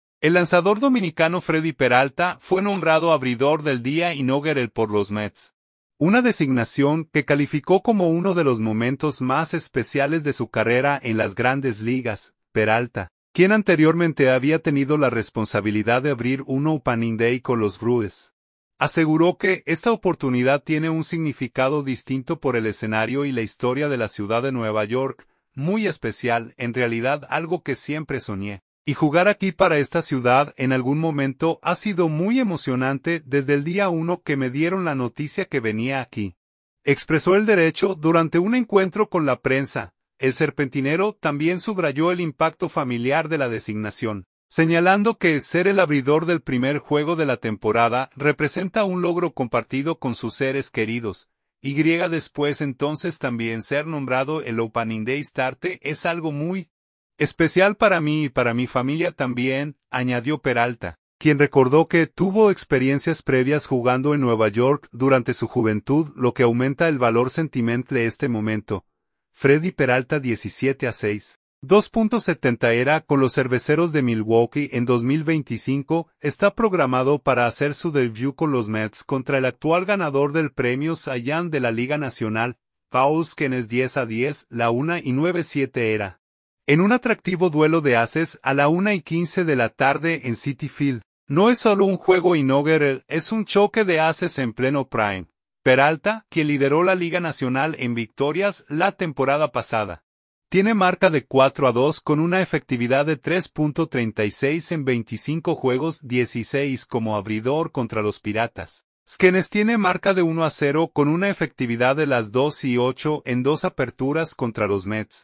“Muy especial, en realidad algo que siempre soñé, y jugar aquí para esta ciudad en algún momento ha sido muy emocionante desde el día uno que me dieron la noticia que venía aquí”, expresó el derecho durante un encuentro con la prensa.